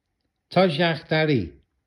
7. тожяхтари